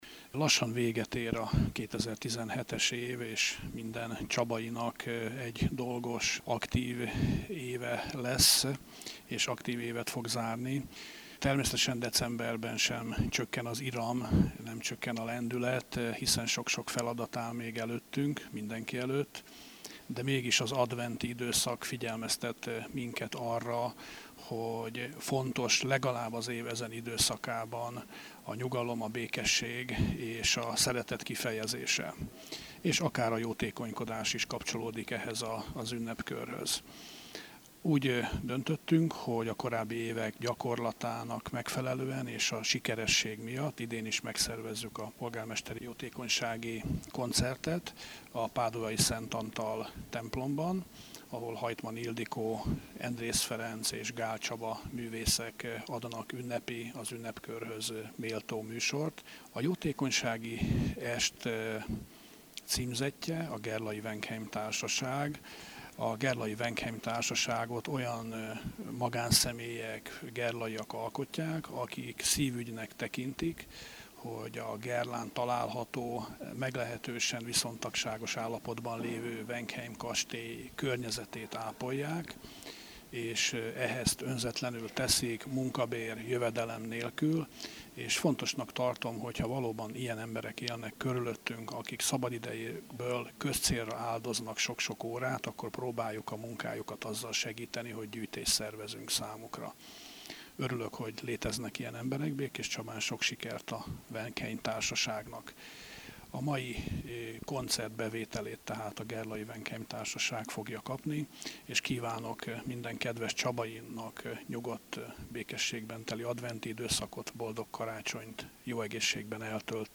énekművészek
zongoraművész
meleg karácsonyi hangulatot varázsoltak a hűvös falak közé